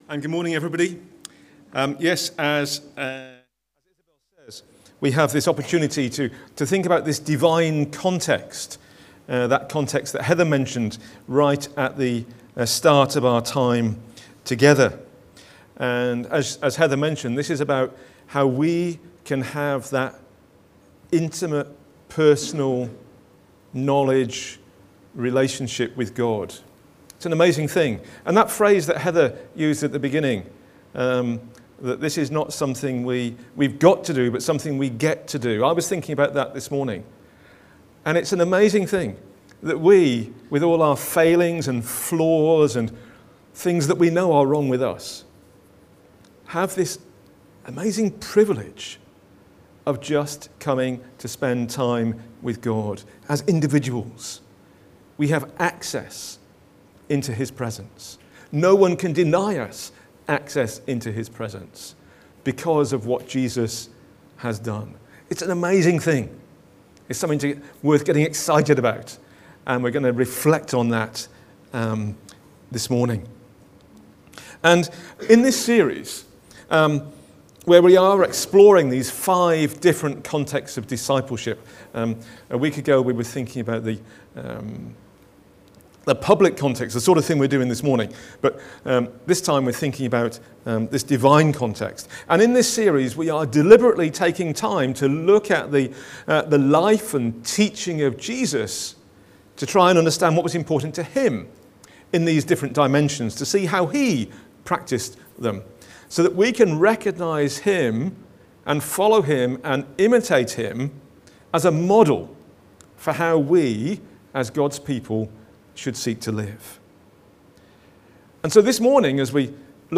Short, child-friendly, talk about carrying the yoke of Jesus